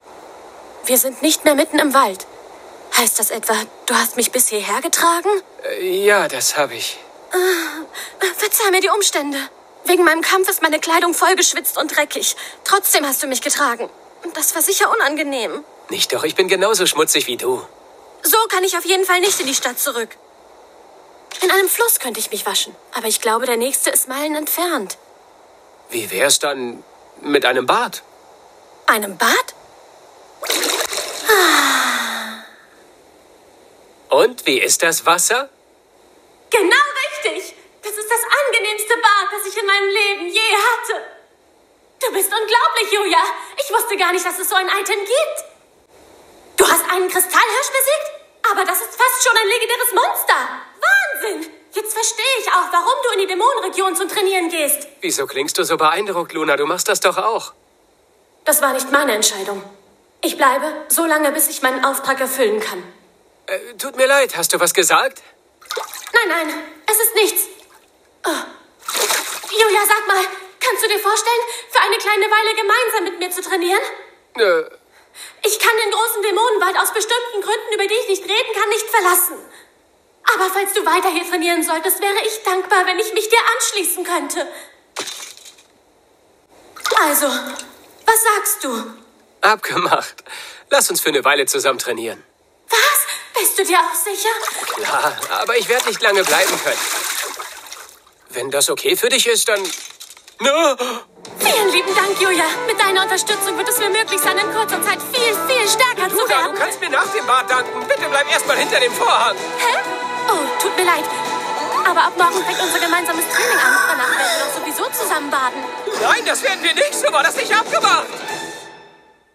Animation
Home Studio professionnel.
Microphone : Shure SM7B
HauteMezzo-sopranoSoprano